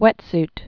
(wĕtst)